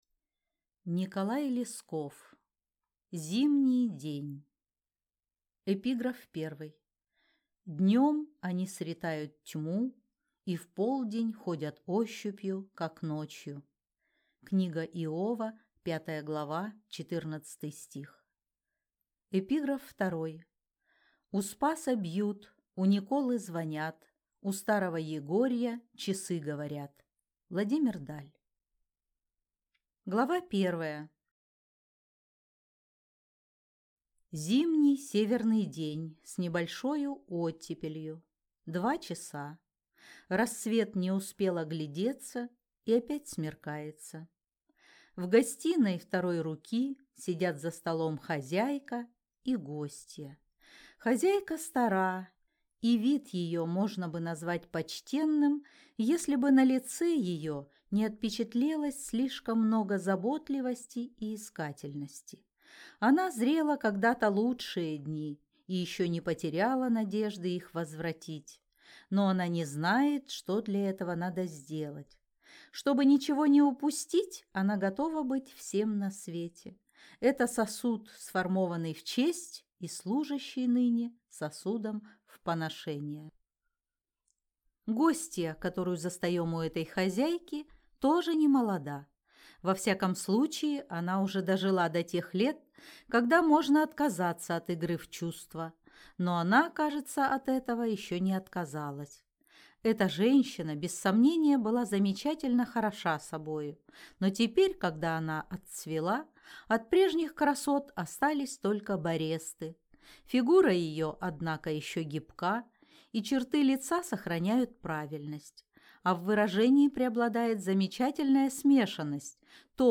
Аудиокнига Зимний день | Библиотека аудиокниг